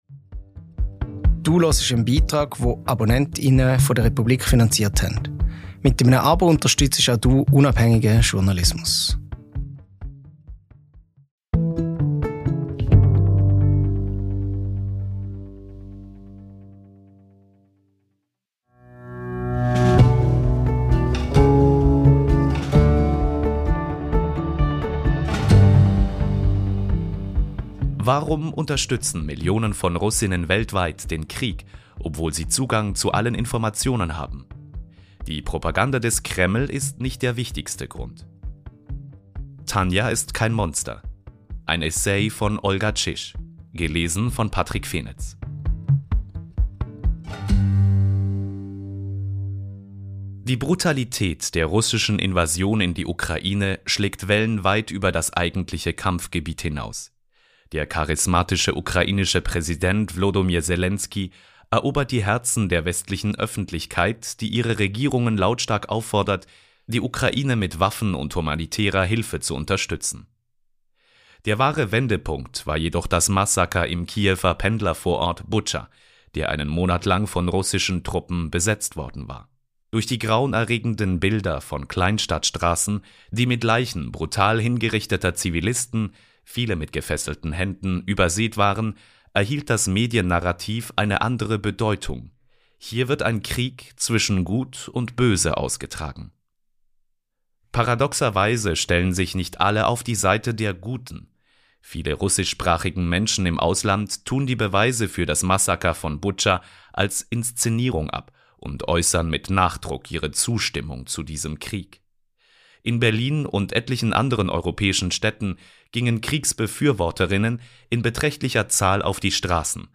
Ein Essay